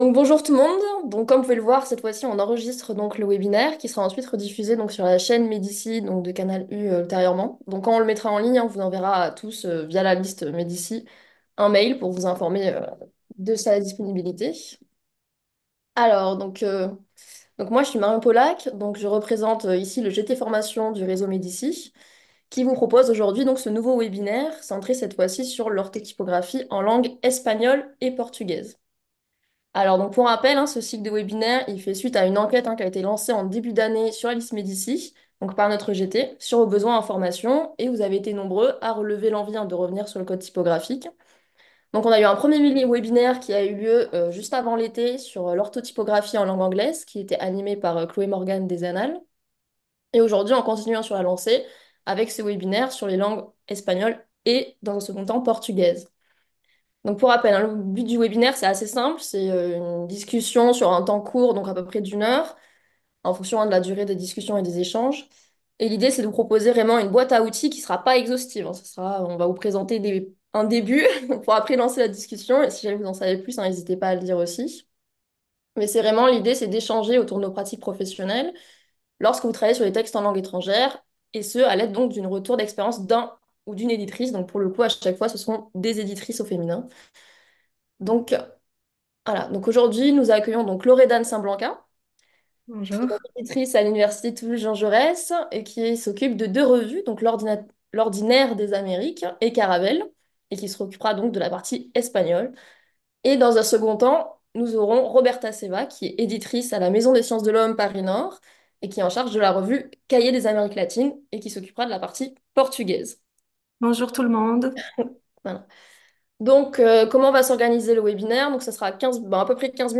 Présentation par un·e intervenant·e : un retour d’expérience de l’intervenant·e pour ouvrir le dialogue et susciter des réflexions.
Temps d’échange : une partie importante du webinaire sera consacrée aux échanges entre participant·es, permettant de partager pratiques, questionnements et astuces.